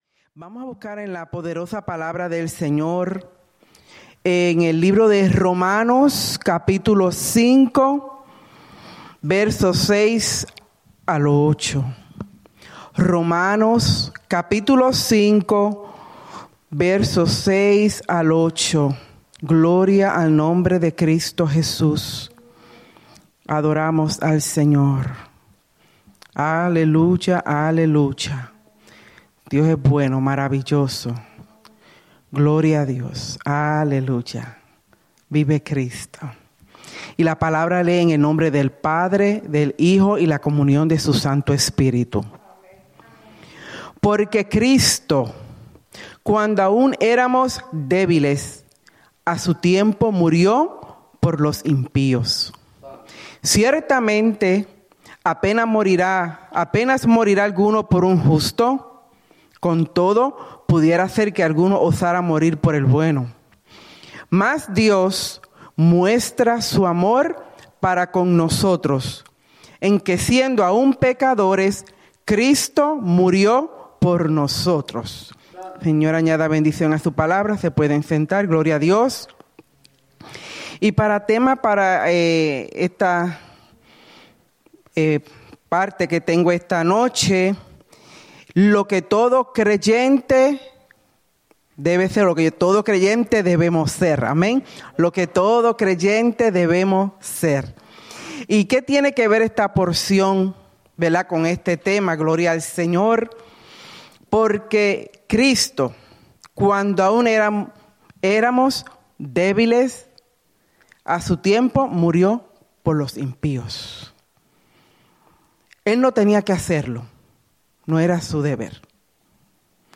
Souderton, PA